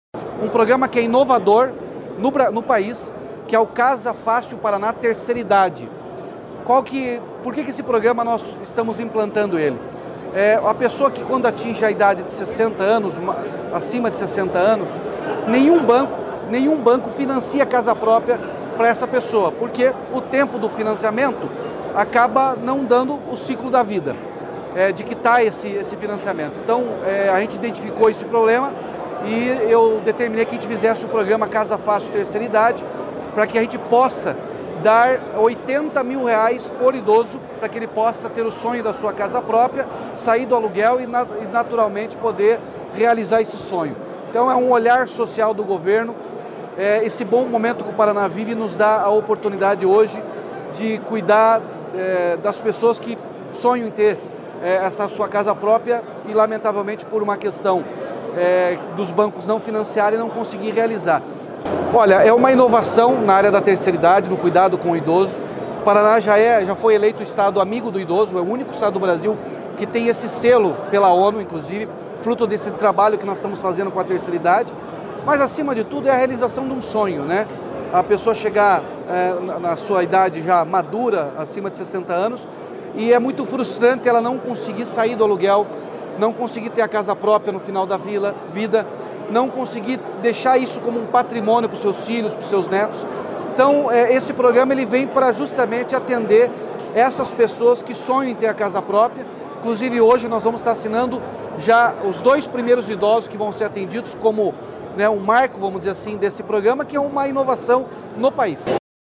Sonora do governador Ratinho Junior sobre o lançamento do Casa Fácil Paraná Terceira Idade